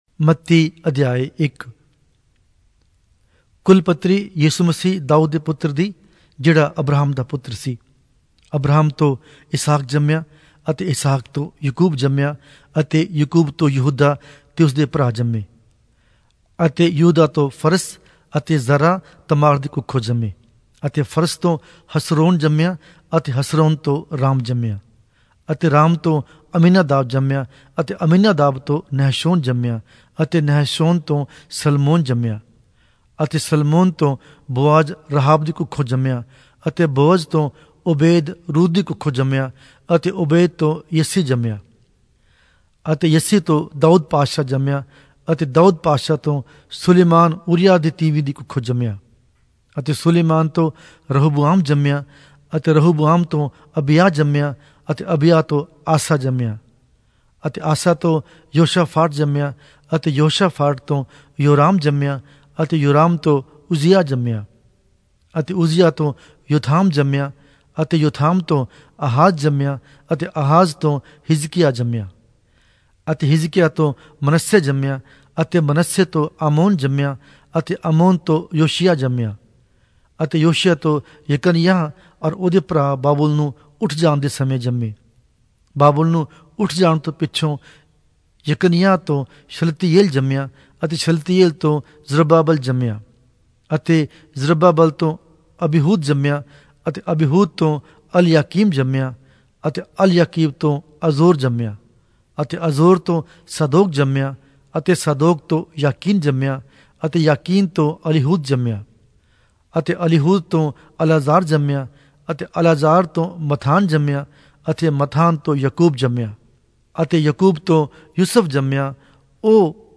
Punjabi Audio Bible - Matthew 8 in Gntbrp bible version